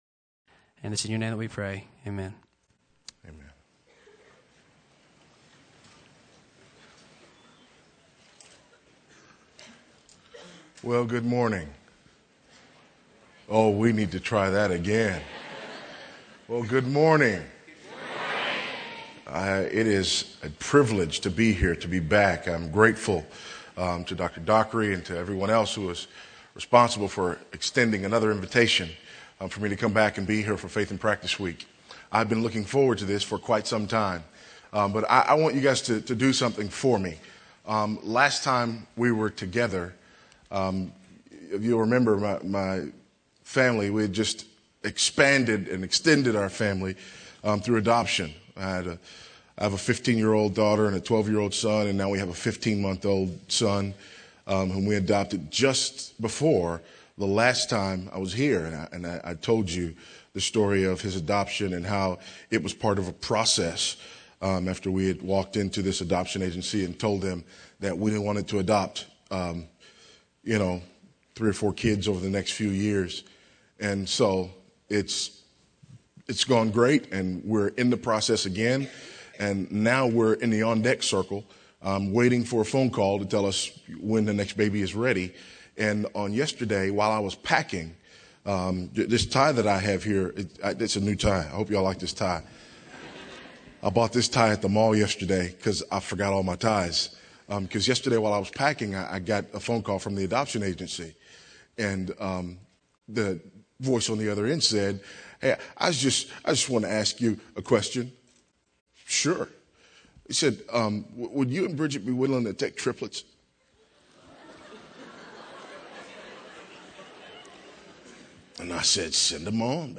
Faith in Practice Conference
Address: "The Nature of the Family" Recording Date